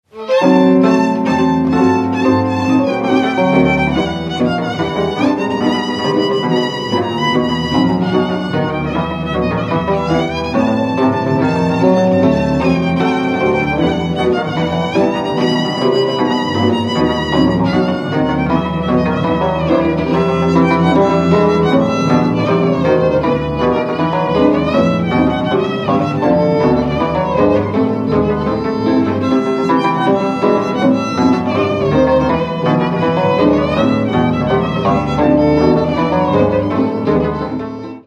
Dallampélda: Hangszeres felvétel
Felföld - Heves vm. - Hort
Műfaj: Lassú csárdás
Stílus: 4. Sirató stílusú dallamok